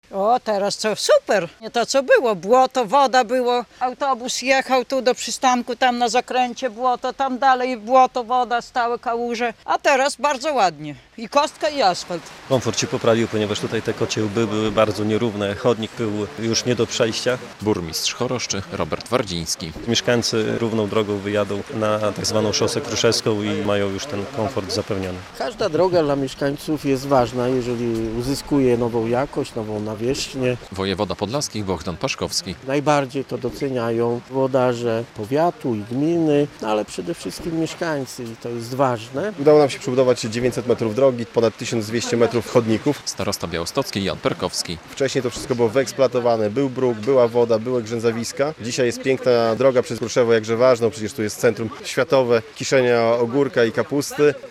W Kruszewie otwarto wyremontowaną drogę powiatową - relacja